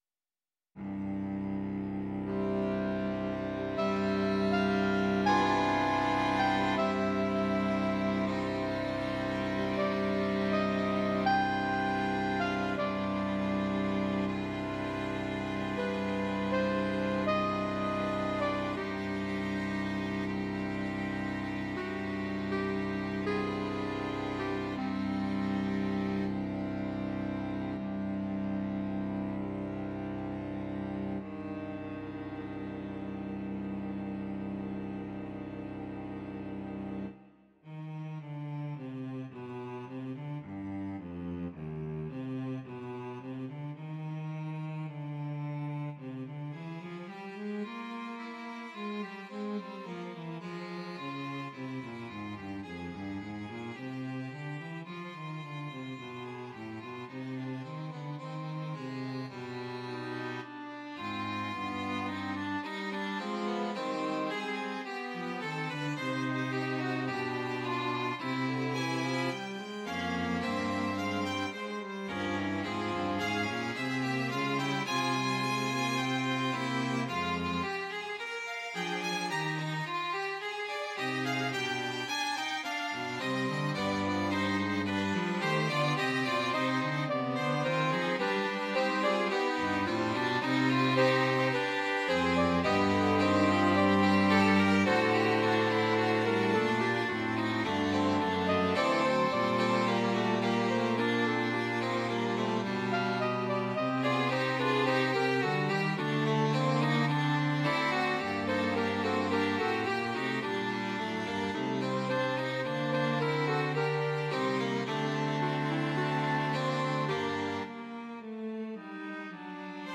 🎧 MP3 previous next menu Allegro ma non troppo ▶ 7 plays Scherzo- Presto ▶ 17 plays Fugue- Andante ▶ 20 plays Allegro con moto ▶ 17 plays previous > next